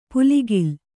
♪ puligil